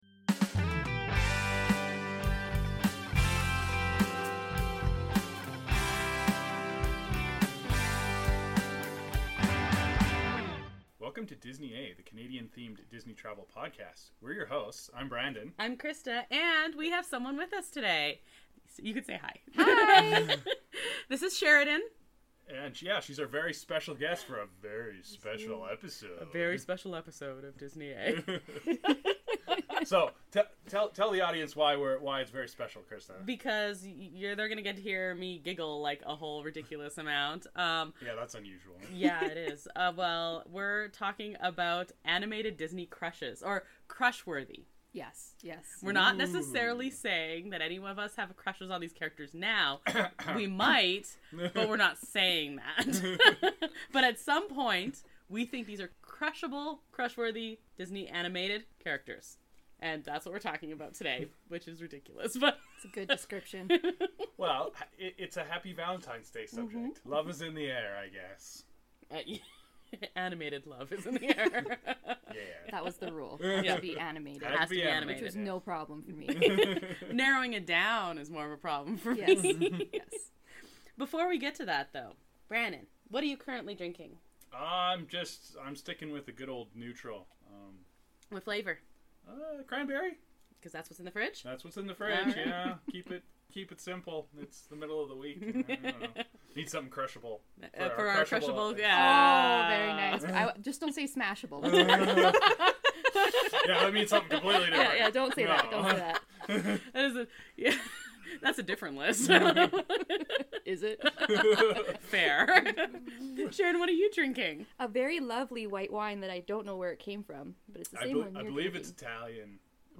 There is much squealing and giggling, and maybe a few risque comments! We've each picked 3 characters (and then a whole lot of honourable mentions).